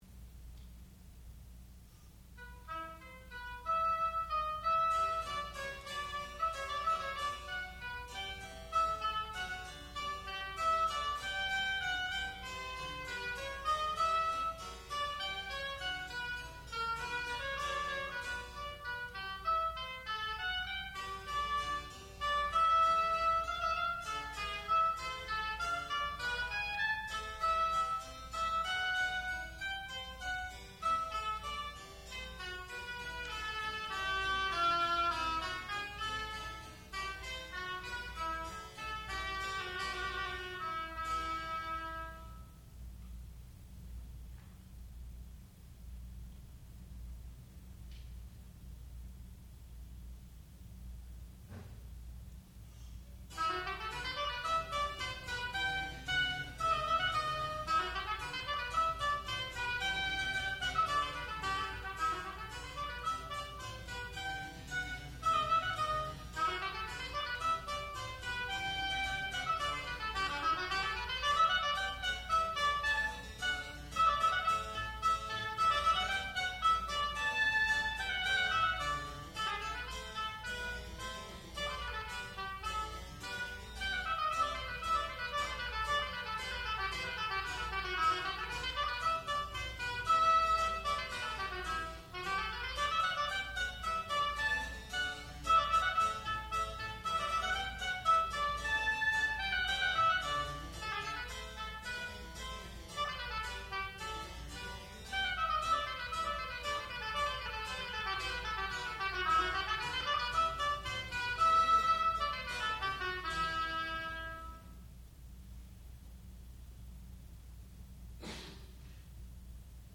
classical music
oboe
harpsichord
Graduate Recital